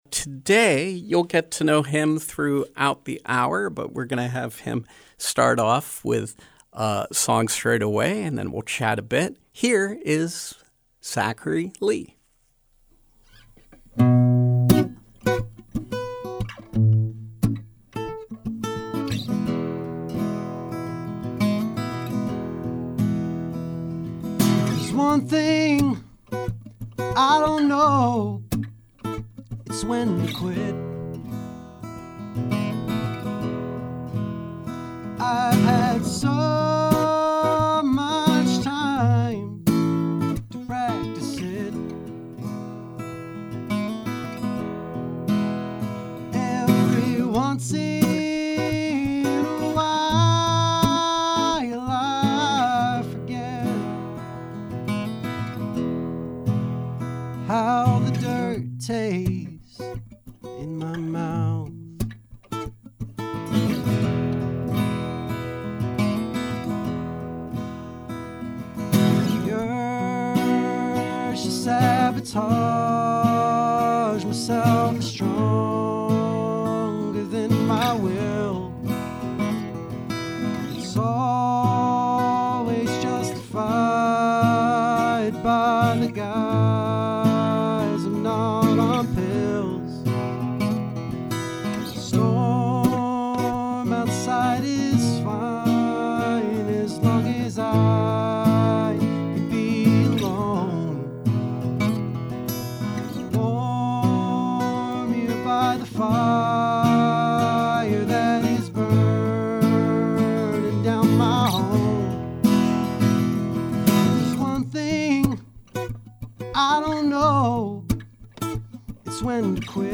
A solo acoustic set
indie rock